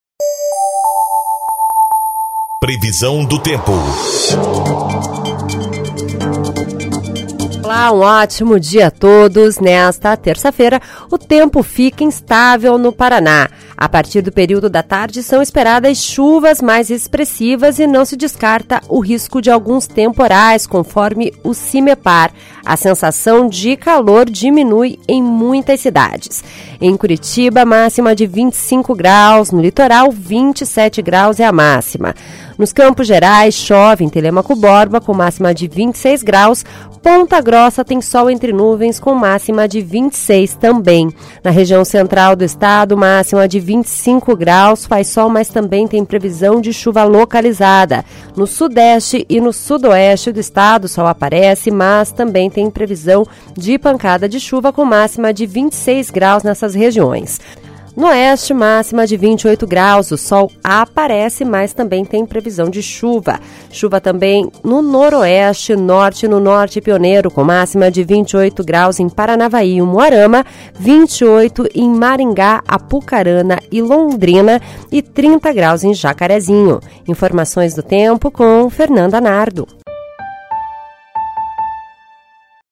Previsão do Tempo (05/12)